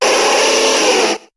Cri de Feunard dans Pokémon HOME.